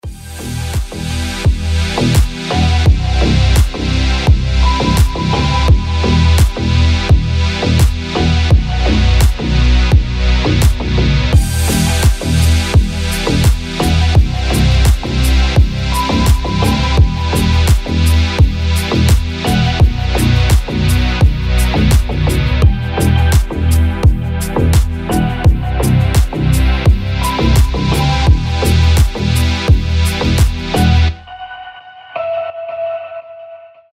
• Качество: 320, Stereo
deep house
без слов
low bass
медленные
космические